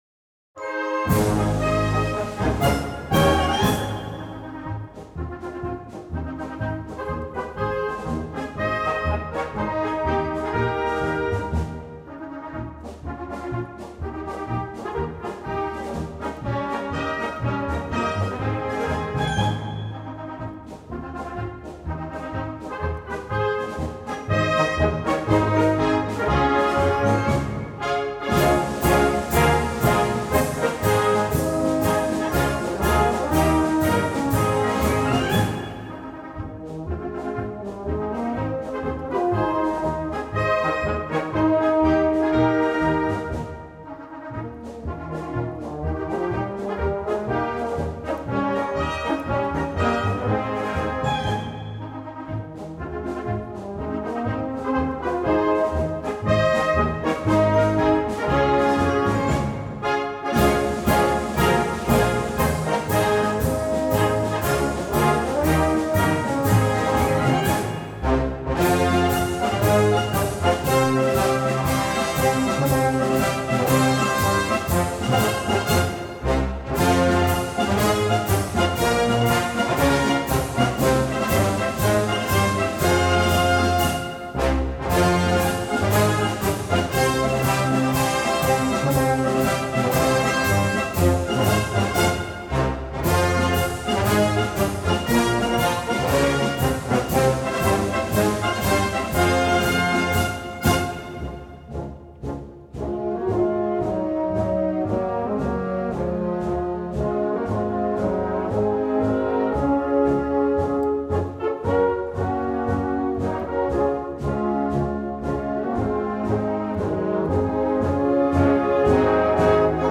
Gattung: Marsch für Blasorchester
Besetzung: Blasorchester
Das Trio kann wahlweise gespielt oder gesungen werden.